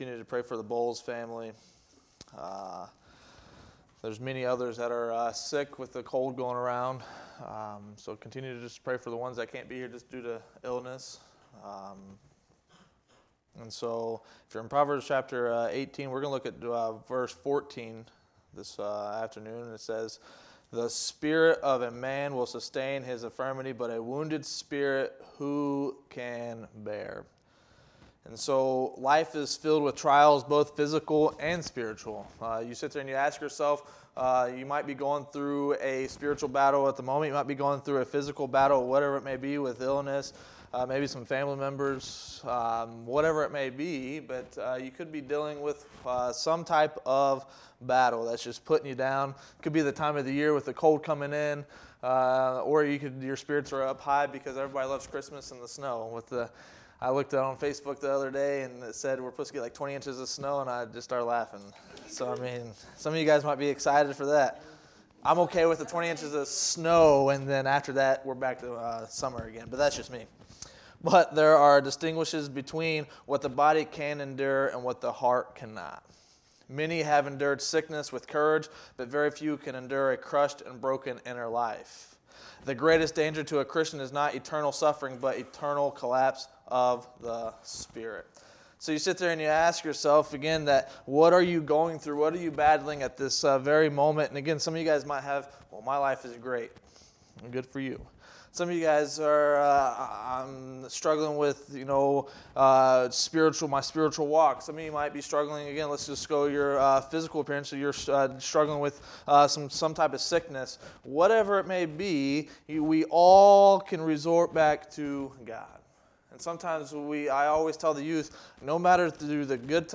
Wednesday Noon Bible Study